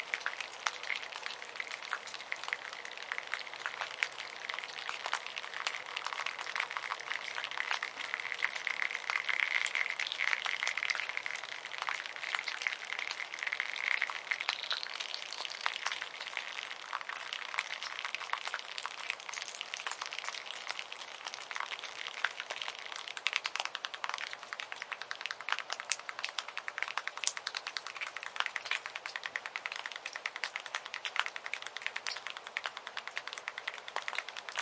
雨流22秒
描述：在暴雨期间流的声音
Tag: 小溪 汩汩 河流 咿呀学语 飞溅 小溪 液体 现场记录 放松 涓涓细流 阴雨